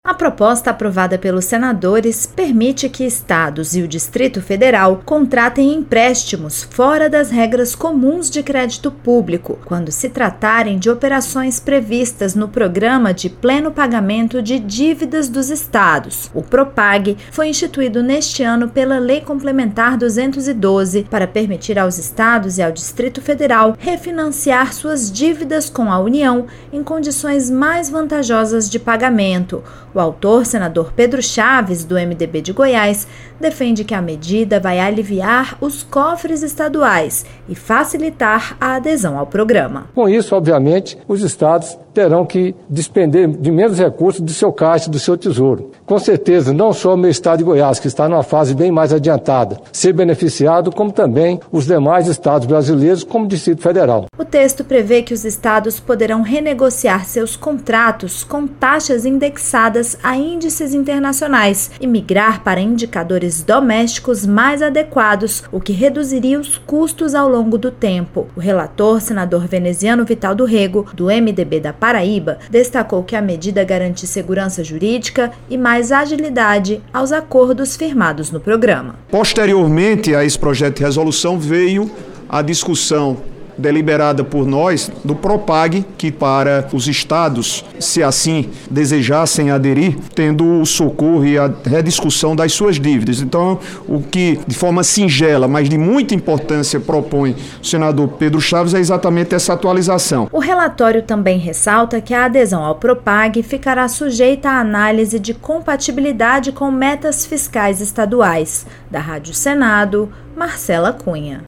O relator, senador Veneziano Vital do Rêgo (MDB-PB), defendeu o projeto por garantir a migração de contratos com taxas internacionais.